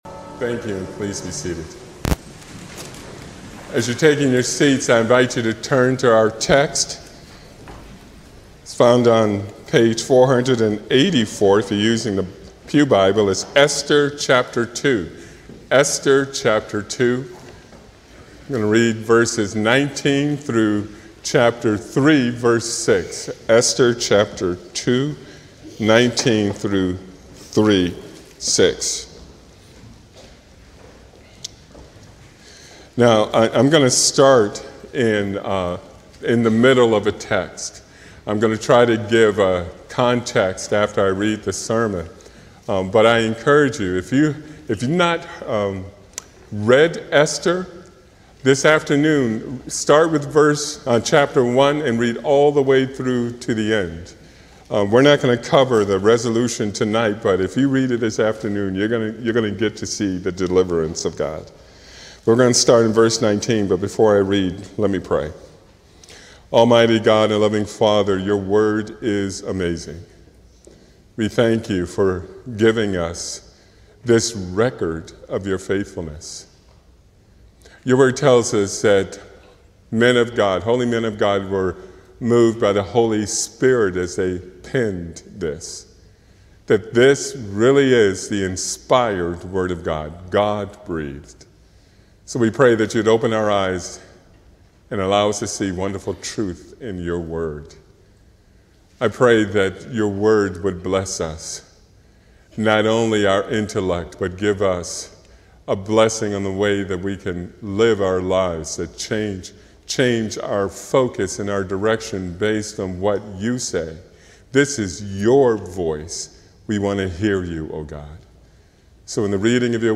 A sermon from the series "Esther."